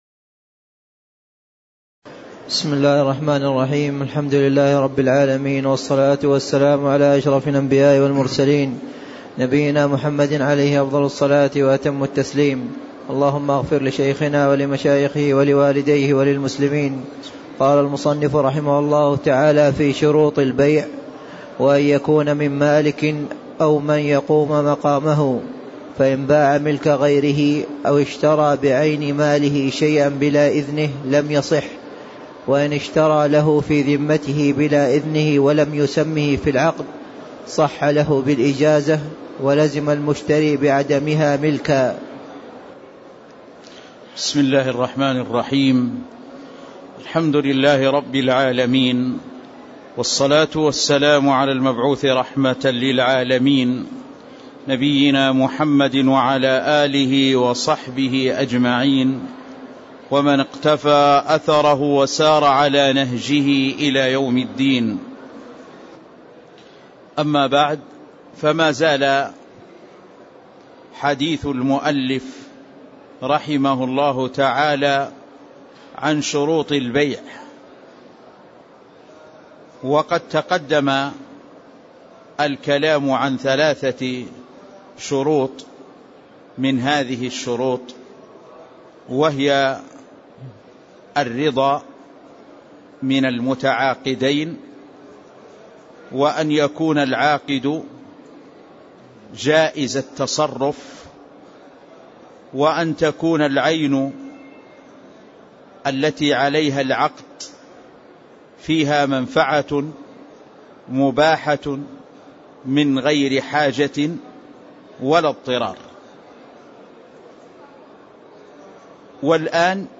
تاريخ النشر ١١ جمادى الآخرة ١٤٣٦ هـ المكان: المسجد النبوي الشيخ